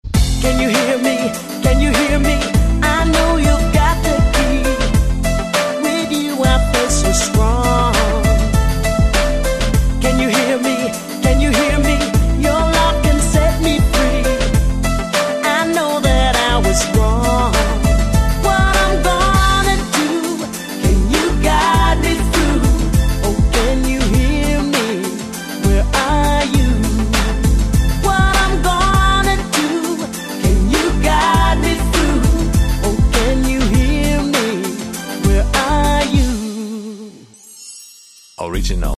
• Качество: 128, Stereo
поп
женский вокал
dance
спокойные
евродэнс